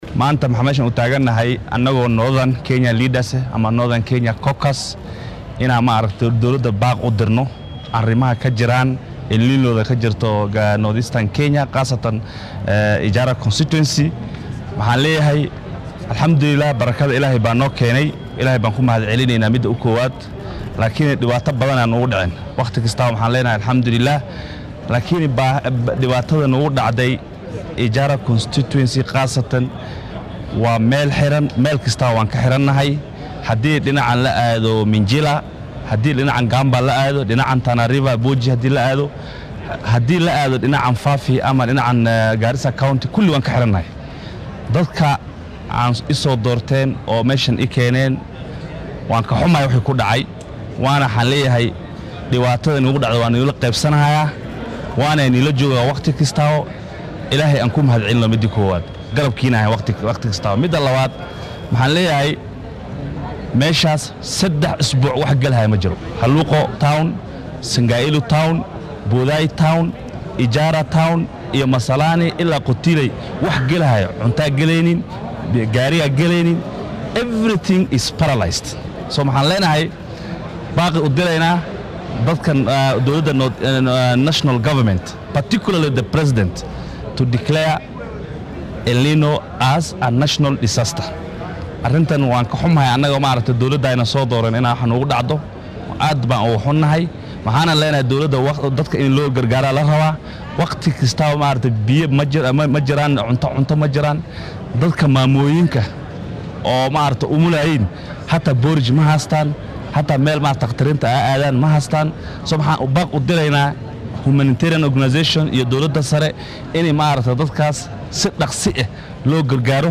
Mudanayaasha laga soo doortay deegaannada hoos tago ismaamullada gobolka Waqooyi Bari ee Garissa, Wajeer iyo Mandera oo maanta shir jaraaid qabtay ayaa dowladda dhexe ugu baaqay inay daadadka wadanka ku dhuftay u aqoonsato musiibo qaran.